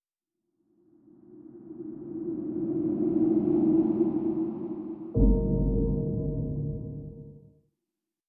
0203_雾气转场.wav